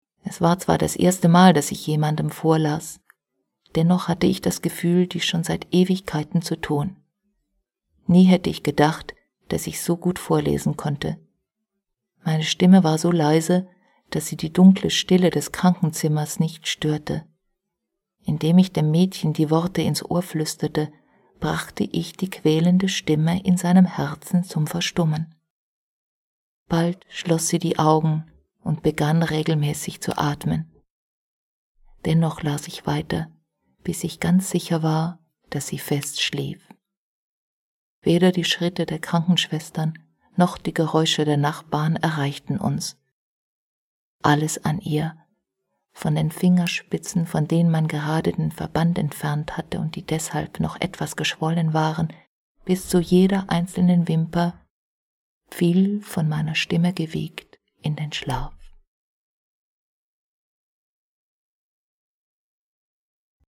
deutschsprachige Sprecherin (Schweiz) Verzauberung: großer Stimmumfang, schöne Modulation!
Sprechprobe: Sonstiges (Muttersprache):
german female voice over artist (switzerland)